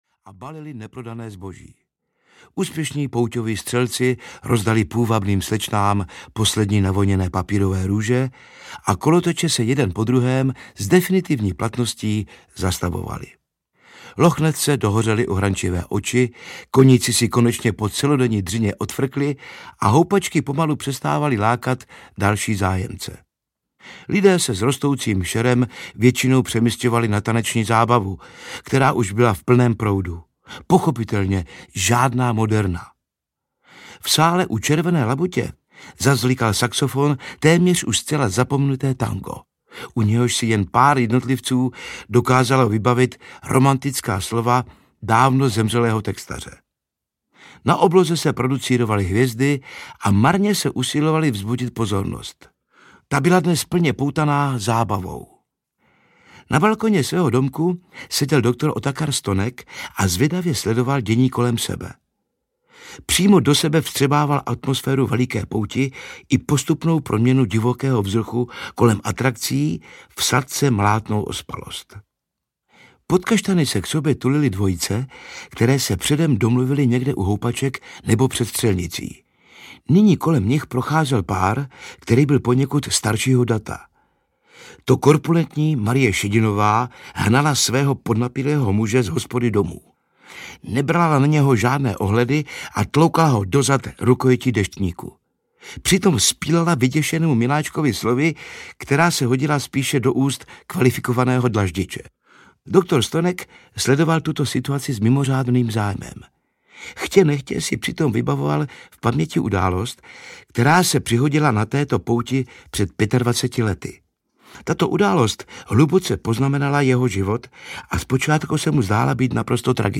Humoristické povídky audiokniha
Humoristické povídky Miloslava Švandrlíka v podání Jiřího Lábuse, Pavla Zedníčka, Norberta Lichého a Arnošta Goldflama.
Ukázka z knihy
• InterpretNorbert Lichý, Jiří Lábus, Pavel Zedníček, Arnošt Goldflam